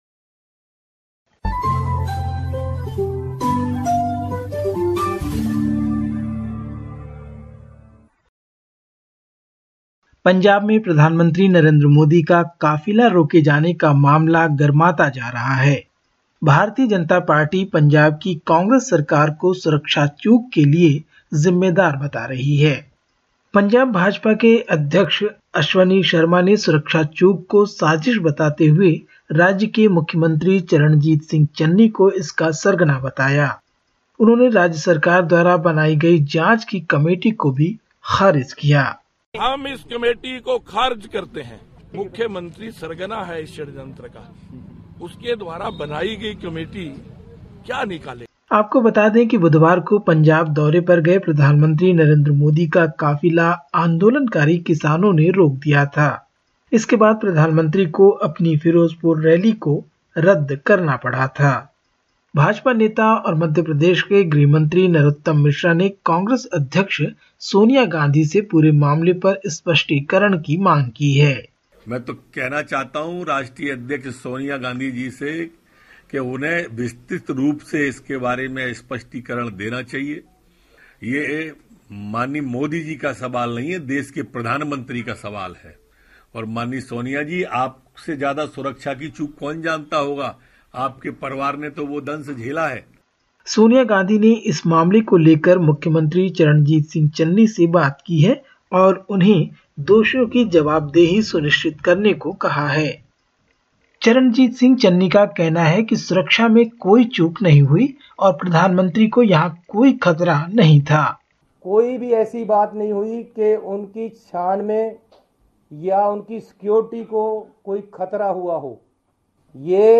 In this latest SBS Hindi report from India: Row escalates between Bharatiya Janata Party and Congress over Prime Minister Narendra Modi's Punjab visit and security concerns; No decision yet on imposing full lockdowns as coronavirus cases spike in Mumbai and Delhi and more.